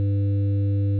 Überlagerung ergibt angenäherte Rechteckschwingung
Das addierte Signal kommt einer Rechteckschwingung schon sehr nahe.